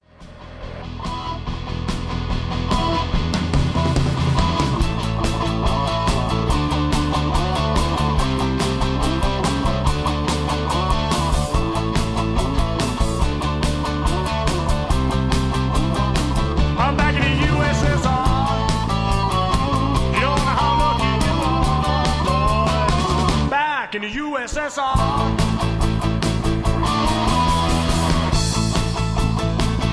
karaoke , backing tracks